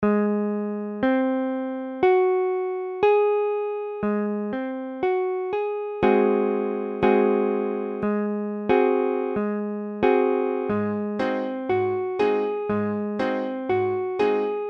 Tablature Ab7.abcAb7 : accord de La bémol septième
Mesure : 4/4
Tempo : 1/4=60
A la guitare, on réalise souvent les accords en plaçant la tierce à l'octave.
La bémol septième barré IV (la bémol case 4 mi bémol case 6 la bémol case 6 do case 5 sol bémol case 7 la bémol case 4)